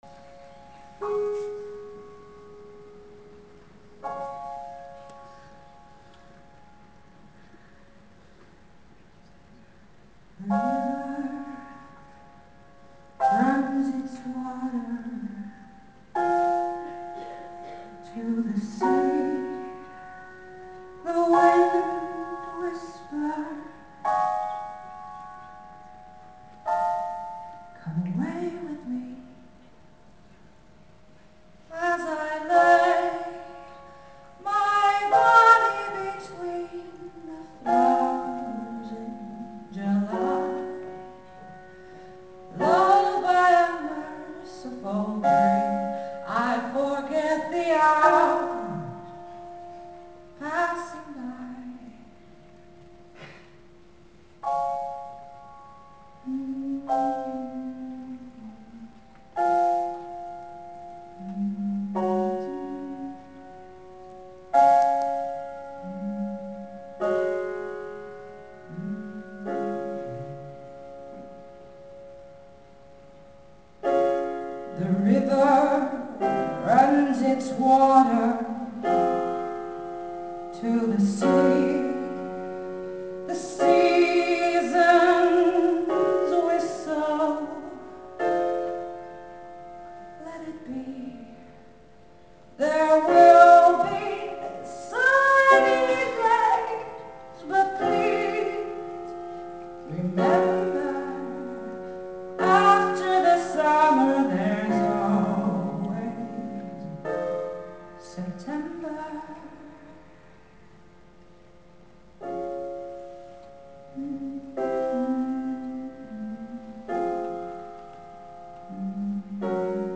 musical Intermezzo